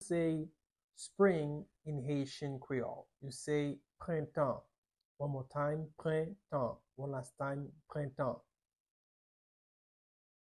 26.How-to-say-Summer-in-Haitian-creole-ete-with-pronunciation.mp3